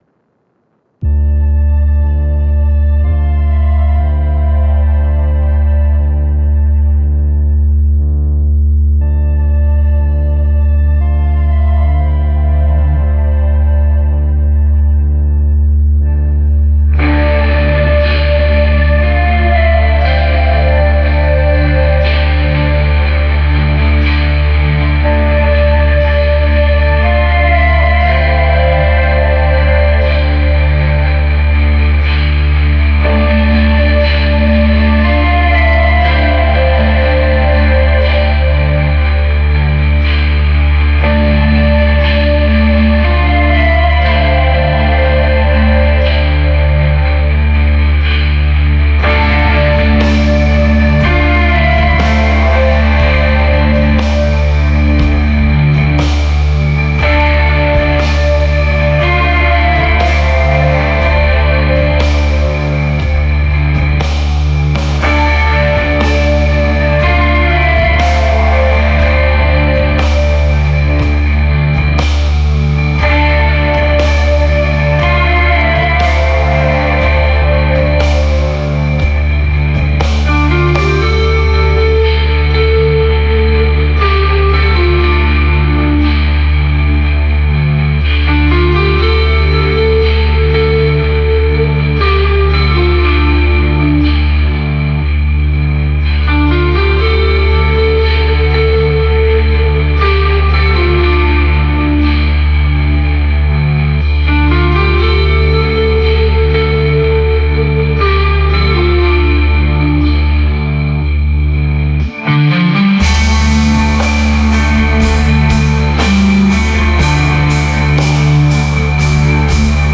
Genre Ambient